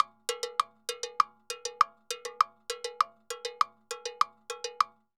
Timbaleta_Salsa 100_3.wav